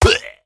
Minecraft Version Minecraft Version 25w18a Latest Release | Latest Snapshot 25w18a / assets / minecraft / sounds / mob / evocation_illager / death2.ogg Compare With Compare With Latest Release | Latest Snapshot
death2.ogg